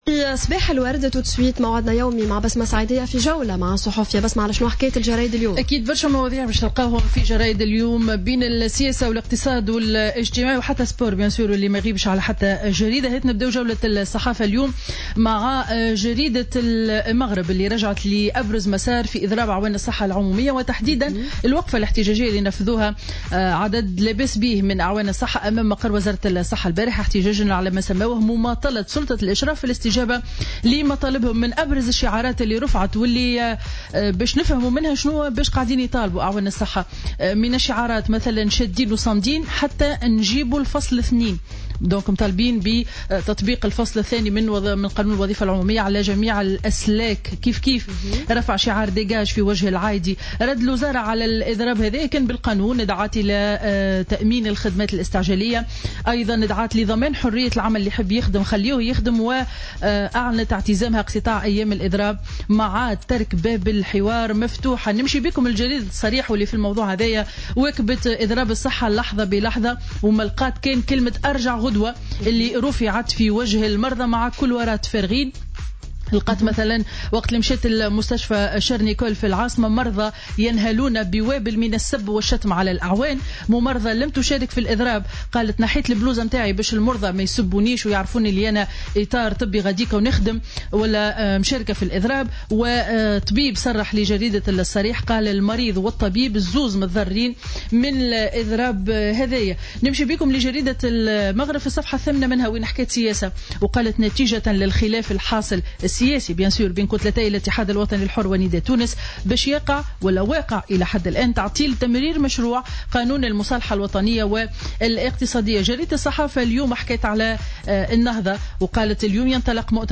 Revue de presse du vendredi 20 mai 2016